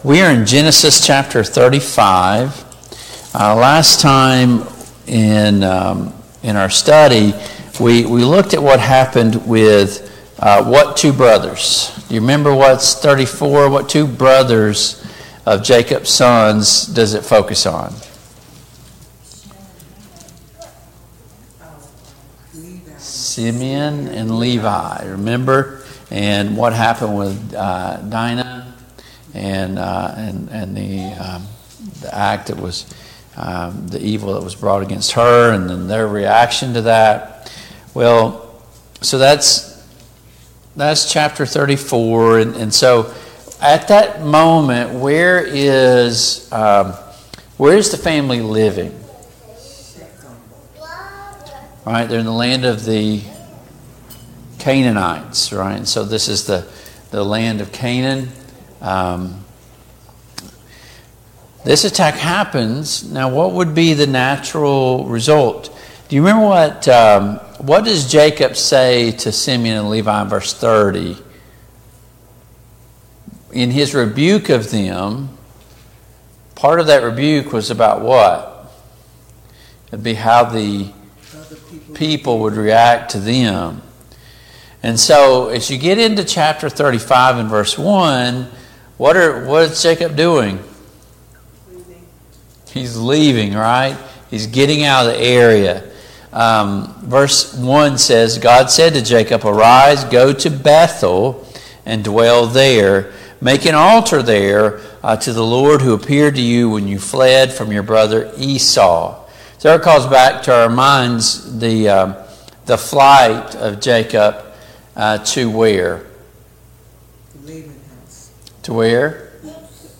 Study of Genesis Passage: Genesis 35 Service Type: Family Bible Hour « A Question of Authority Study of Paul’s Minor Epistles